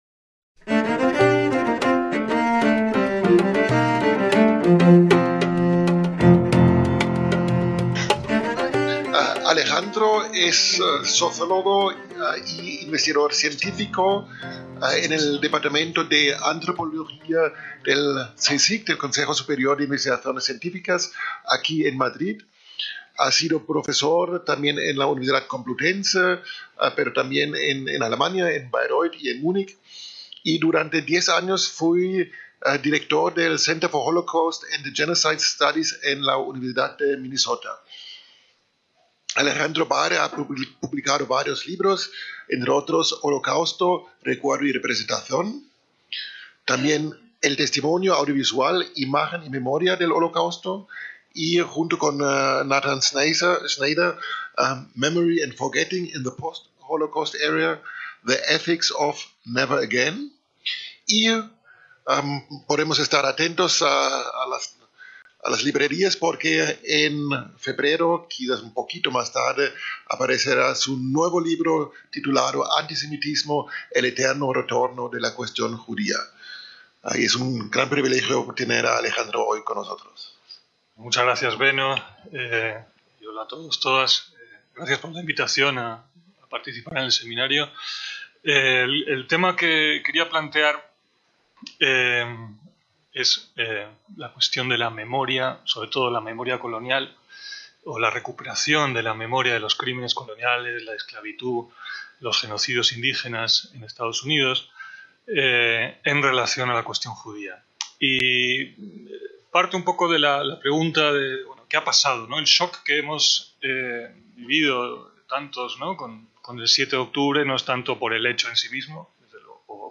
VII SEMINARIO INTERNACIONAL CONTRA EL ANTISEMITISMO